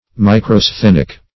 Search Result for " microsthenic" : The Collaborative International Dictionary of English v.0.48: Microsthenic \Mi`cro*sthen"ic\, a. (Zool.) Having a typically small size; of or pertaining to the microsthenes.